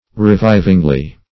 -- Re*viv"ing*ly , adv.
revivingly.mp3